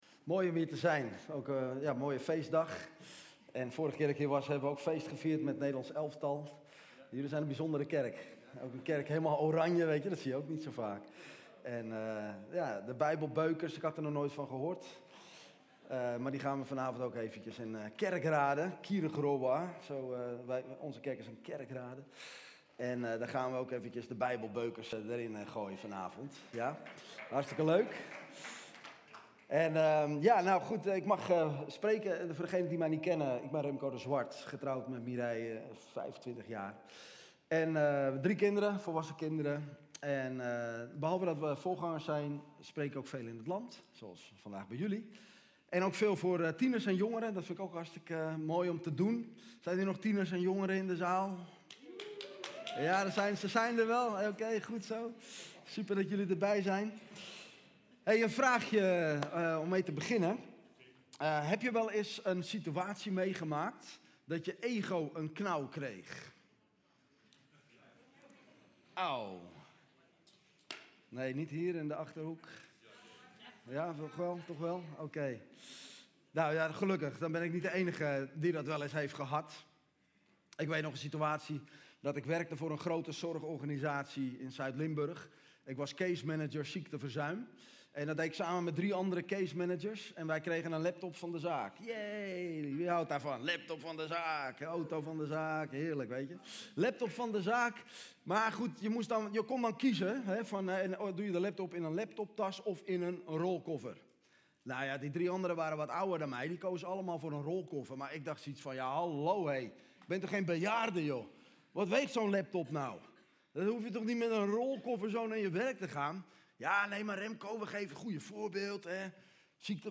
Prekener Podcasts
– Dankzegging Heilig Avondmaal – Dorpskerk Abbenbroek ★ Support this podcast ★Door Geloofstoerusting … continue reading